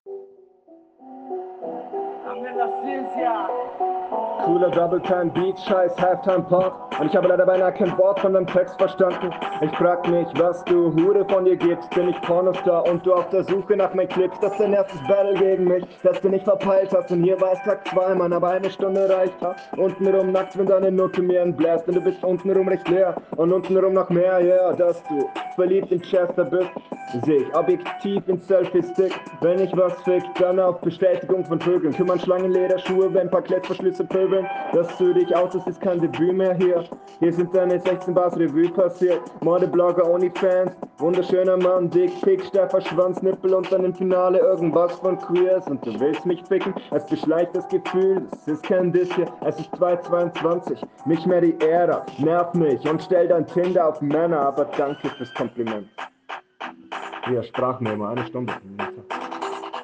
Was für eine Quali...hast du jetzt nicht ernsthaft mit dem Handy aufgenommen lol...Flow und Stimmeinsatz …